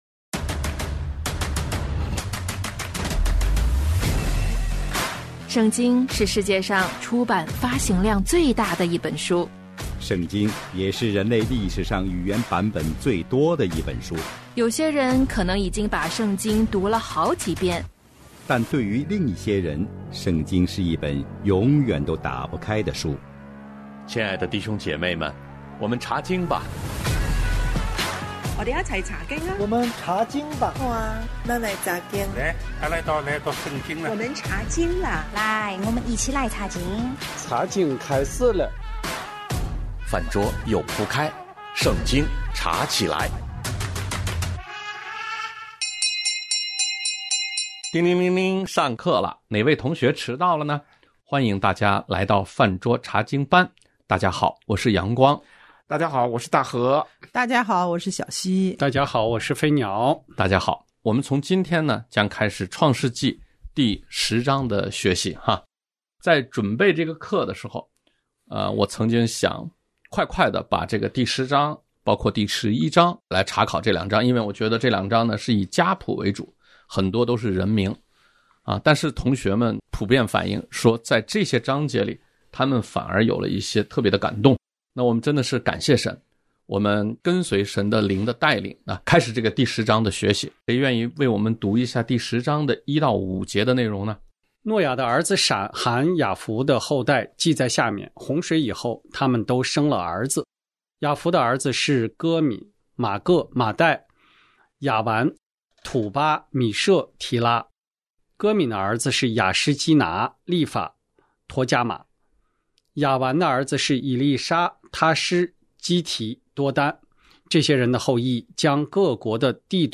《泛桌茶经班》是一个既圣洁又生活，既严肃又活泼的课堂。